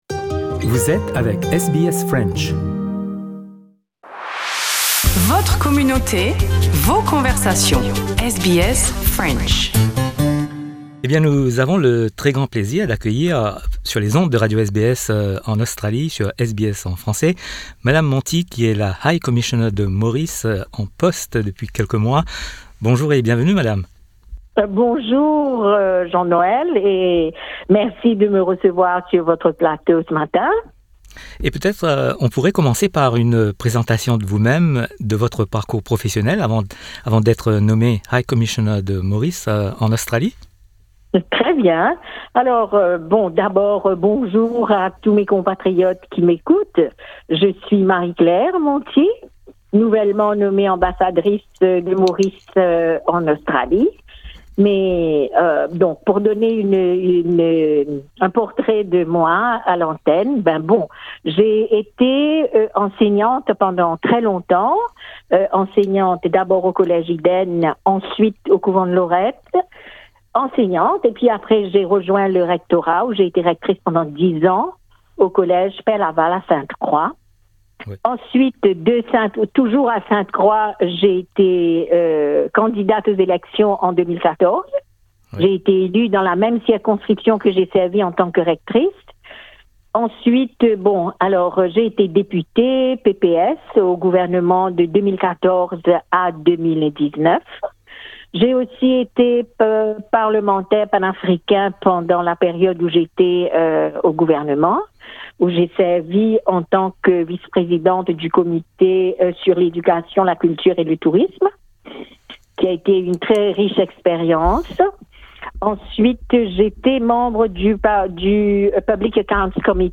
Rencontre avec la nouvelle High Commissioner de Maurice en Australie, Mme Marie-Claire Monty.